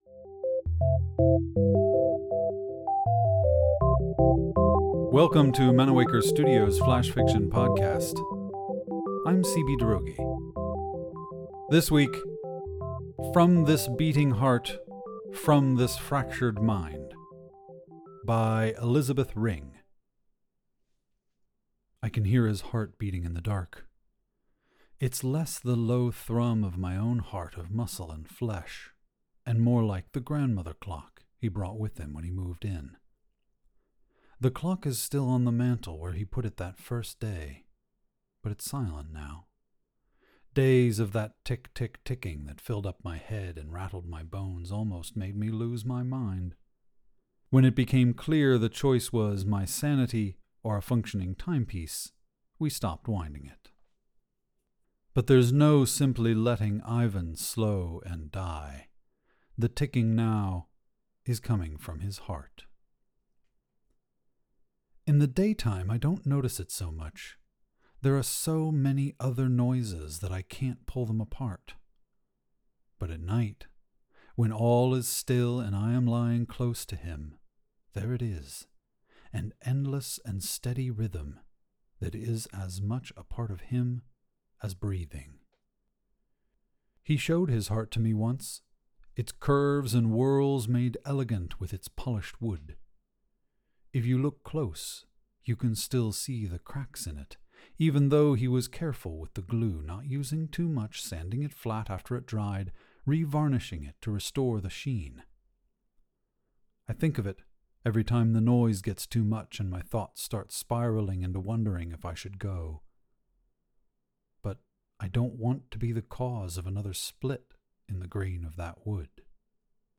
The Flash Fiction Podcast Theme Song is by Kevin McCleod